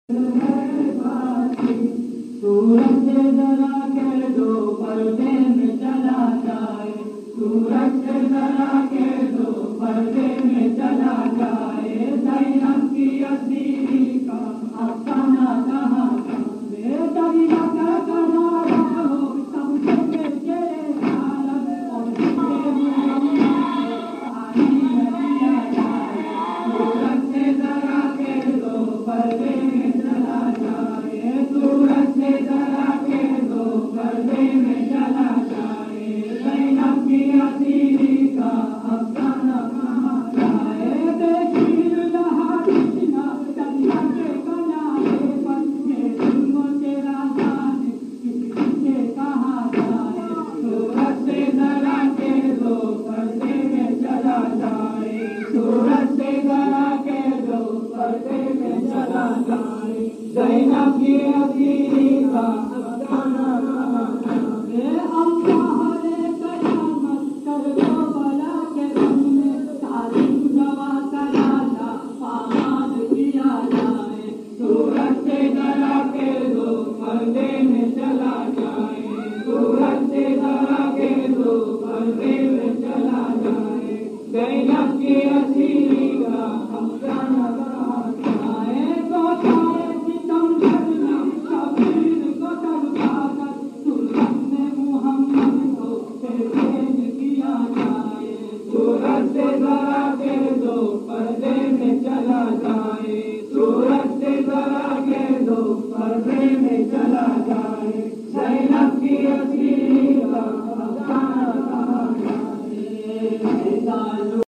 Non Saff Calssic Marsia / Nawha